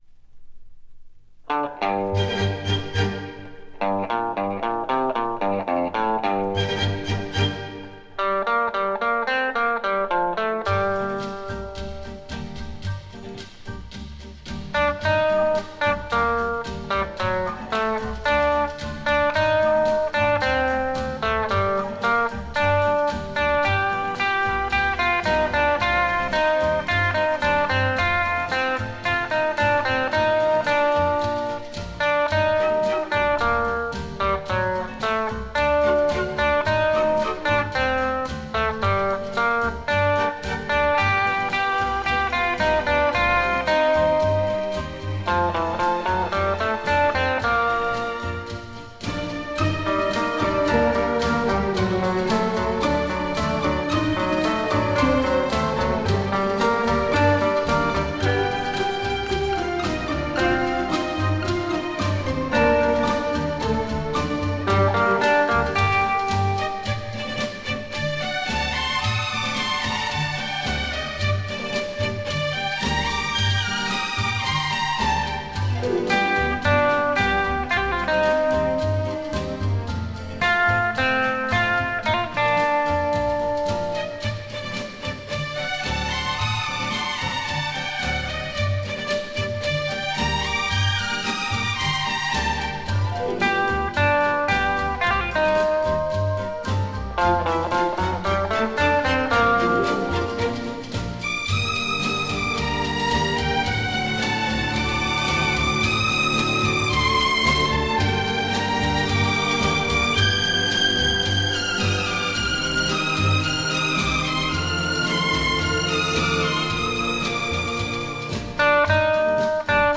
Жанр: Pop, Classical, Easy Listening